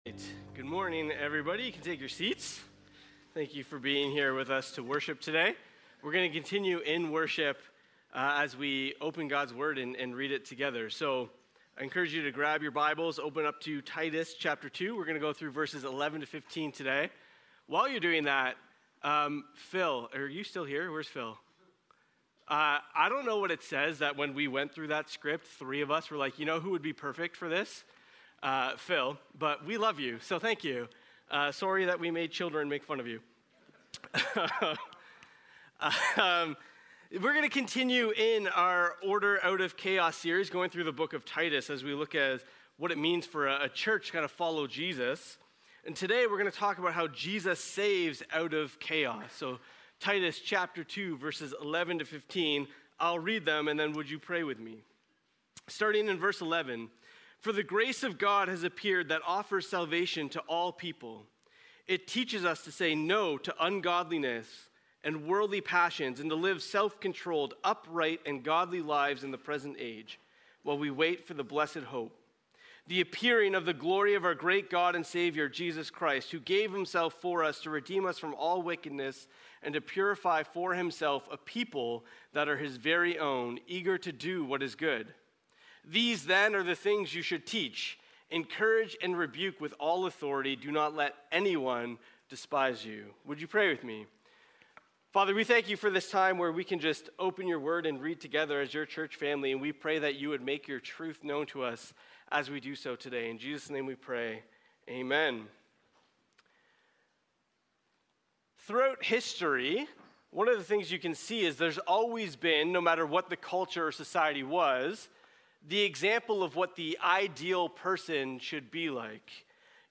Sermons | James North Baptist Church